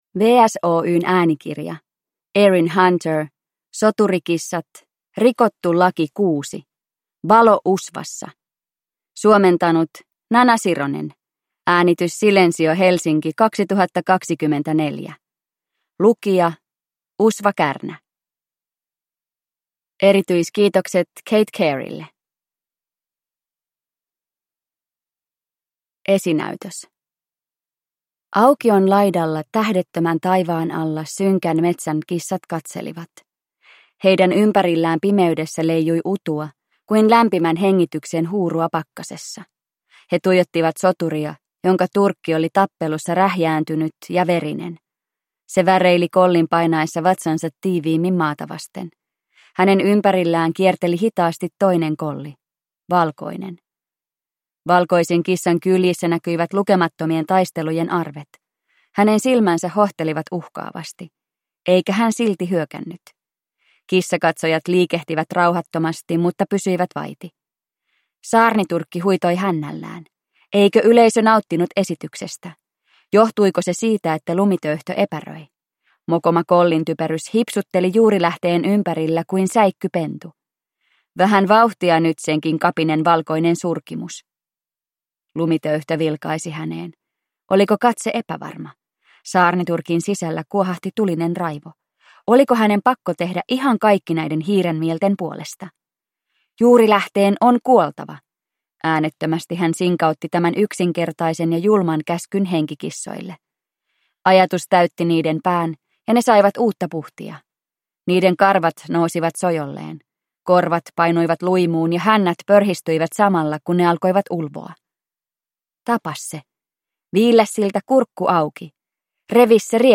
Soturikissat: Rikottu laki 6: Valo usvassa – Ljudbok